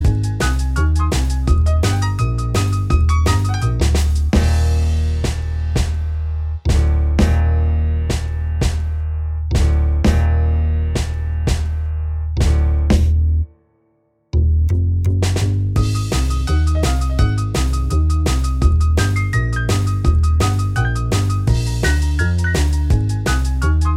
Minus Guitars Pop (1960s) 2:29 Buy £1.50